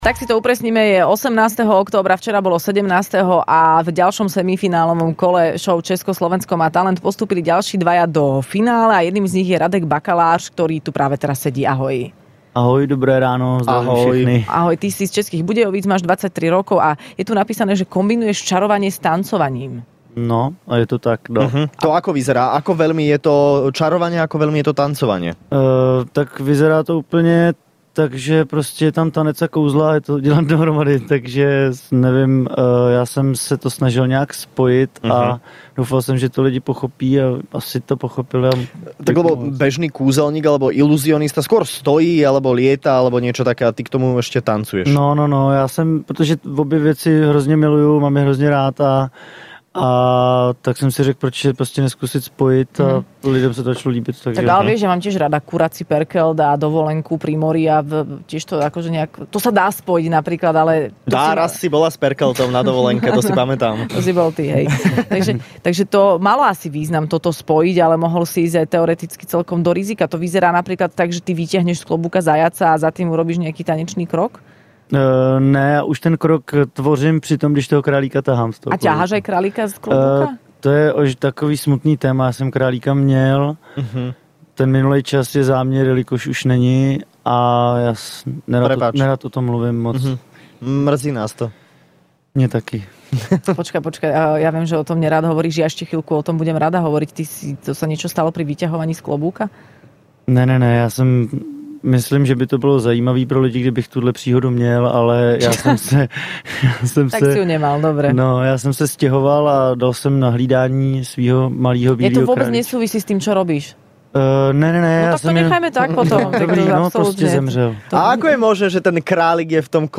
Do Rannej šou prišli dvaja postupujúci semifinalisti zo šou Česko - Slovensko má talent